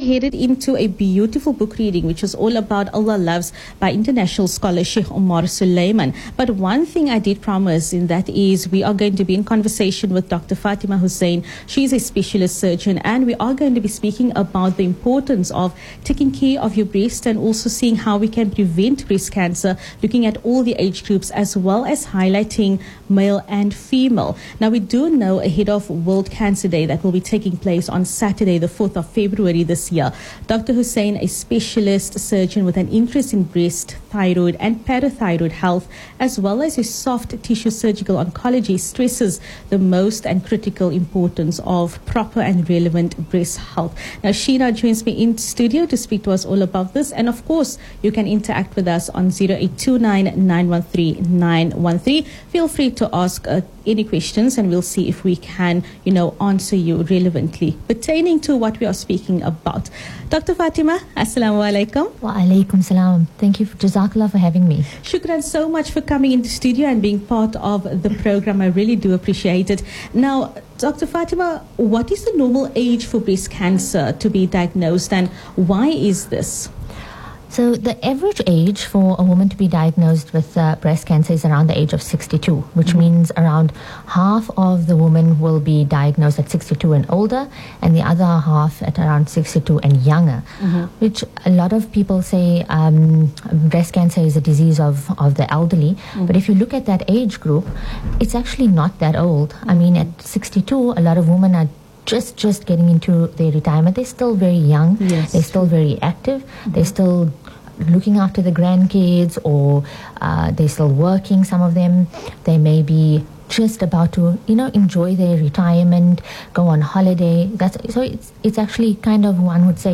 With kind permission from Voice of the Cape, the full interview is available to listen to: